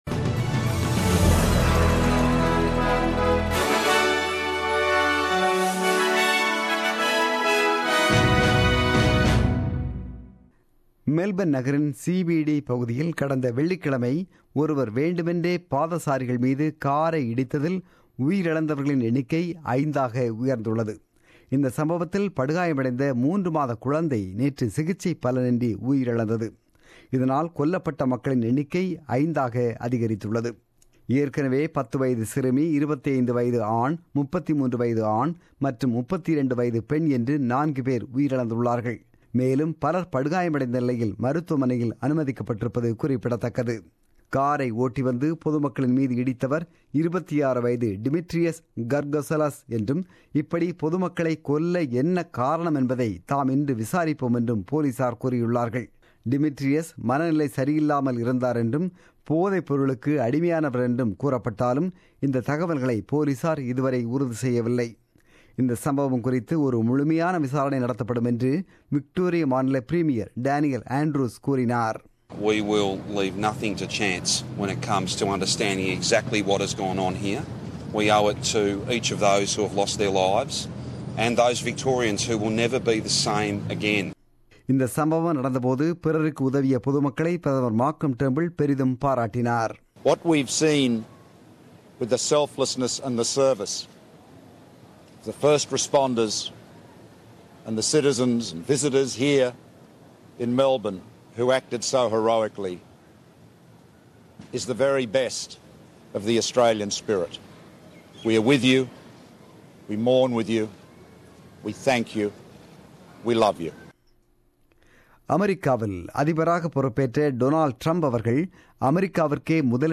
The news bulletin broadcasted on 22 January 2017 at 8pm.